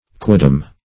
Quidam \Qui"dam\, n. [L.]